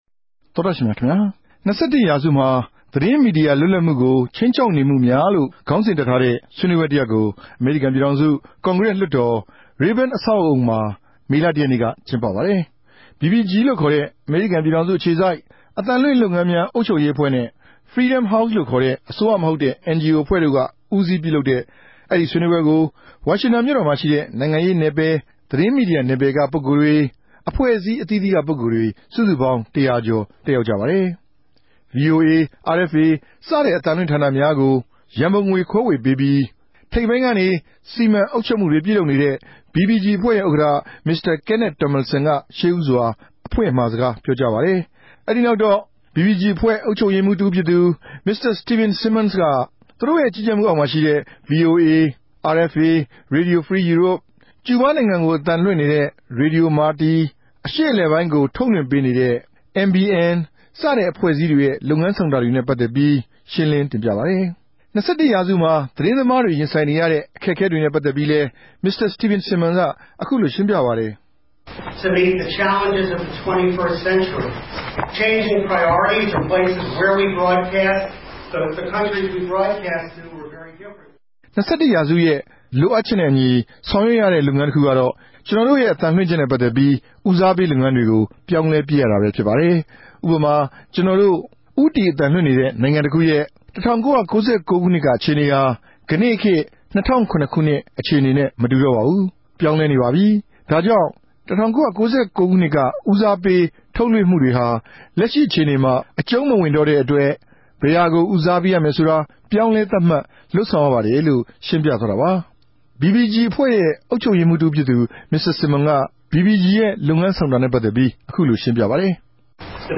အစီရင်ခံ တင်ူပထားတာကိုလည်း နားထောငိံိုင်ပၝတယ်။